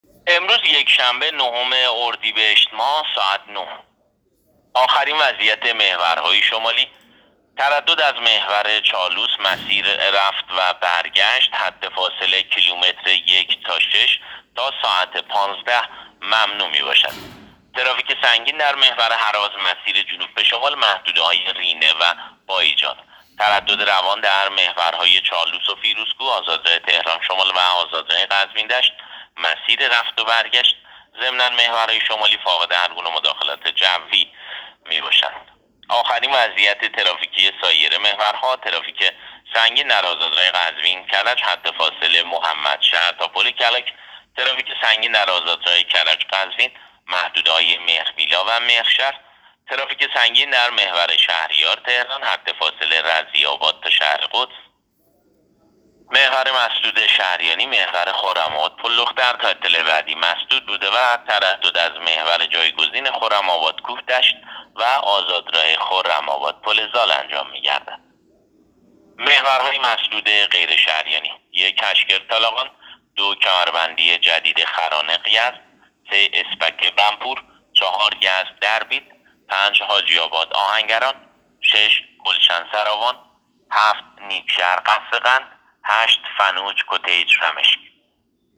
گزارش رادیو اینترنتی از آخرین وضعیت ترافیکی جاده‌ها تا ساعت ۹ روز ۹ اردیبهشت؛